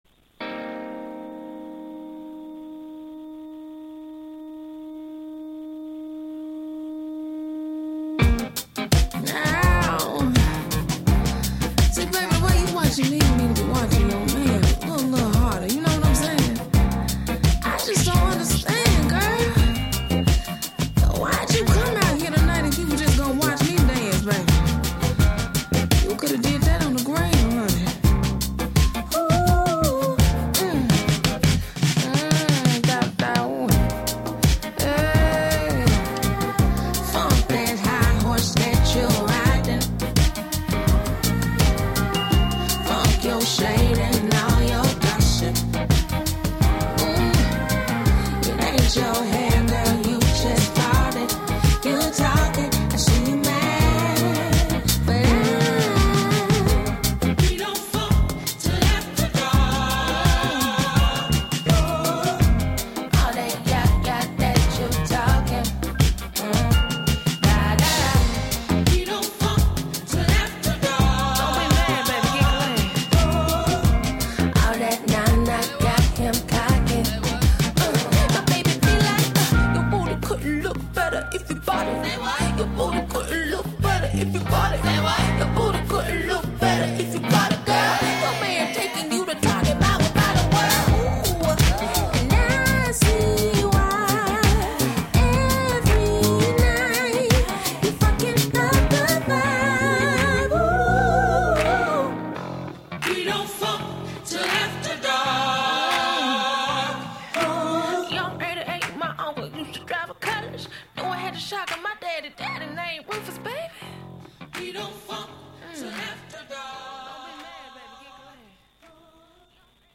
funk, groove, soul